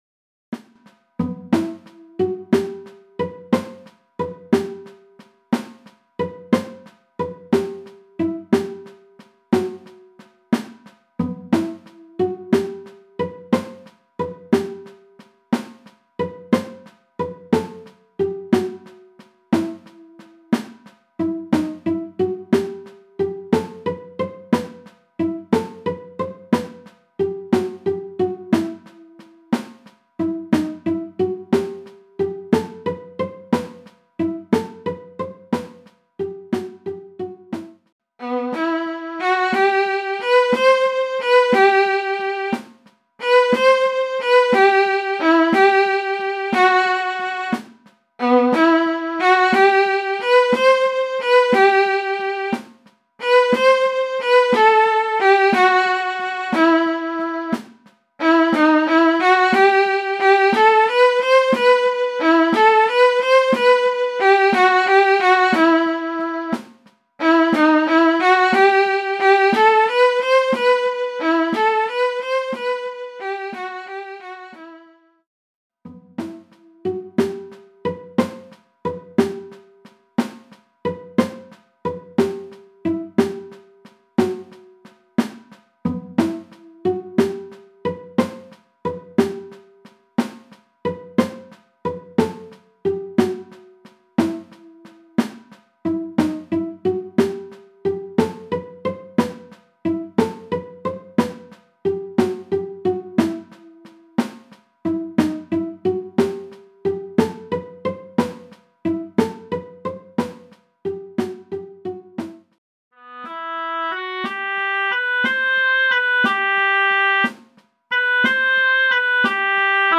MIDI von 2011 [7.460 KB] - mp3